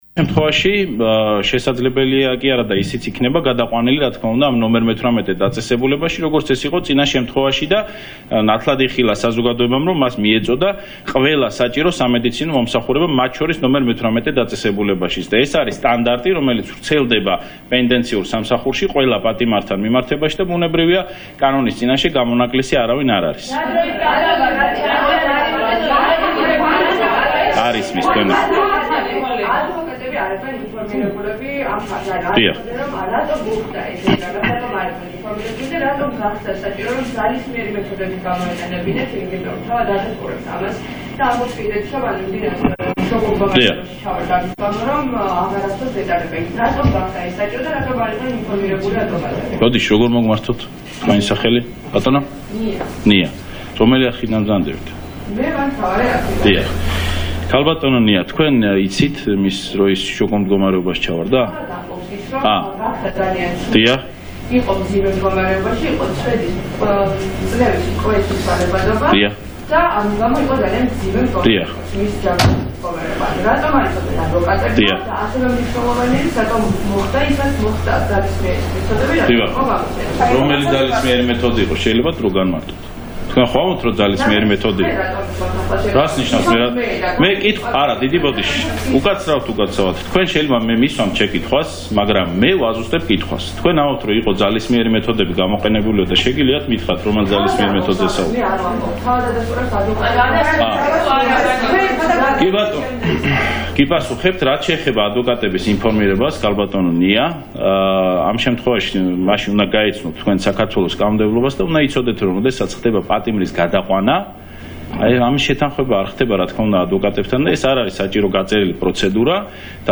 იუსტიციის მინისტრის რატი ბრეგაძის ხმა